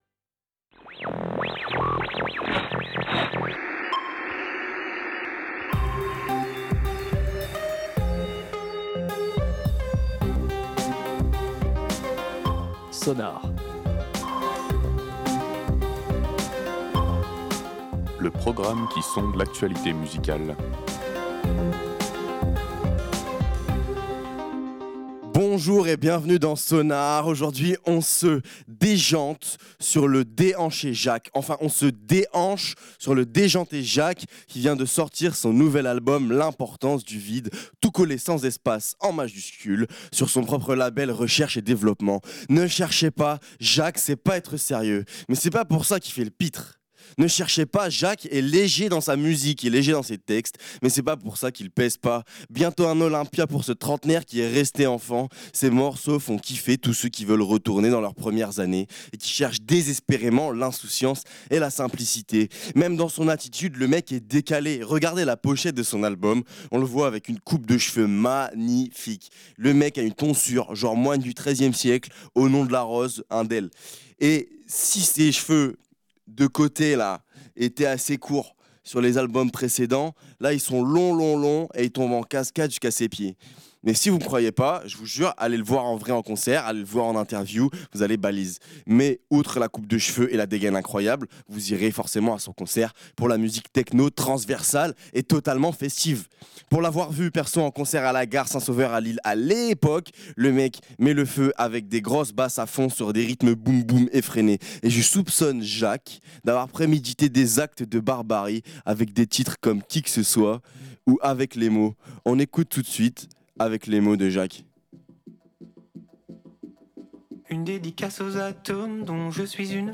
Réécoutez l'émission
Un projet électro léger, chanté, insouciant, de quoi ravir nos oreilles d’adultes contrariés.